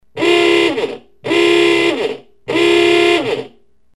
Click horn to honk
The sound you heard is from this horn. I had a problem recording the sound because it was so loud. It sure makes the horn on my 1924 Dodge Brothers truck sound tame.
ooogha.mp3